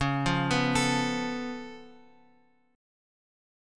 C# Augmented 7th